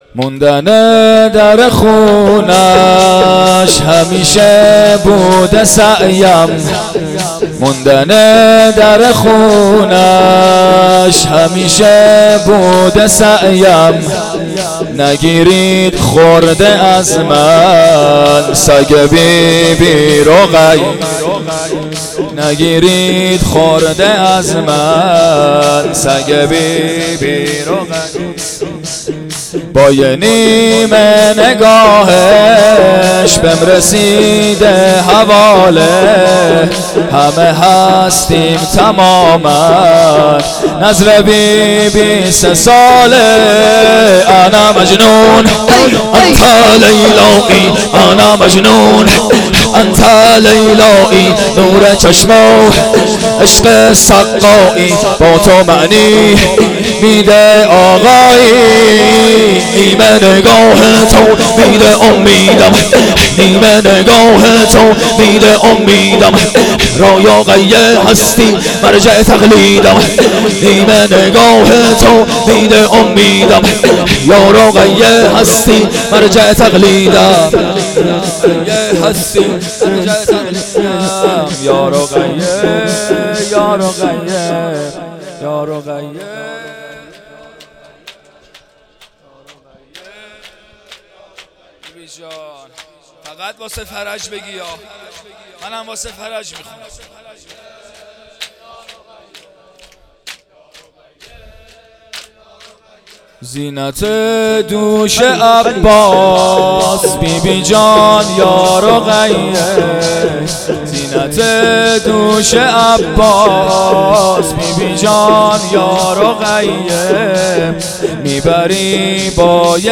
مراسم ایام شهادت حضرت رقیه۹۶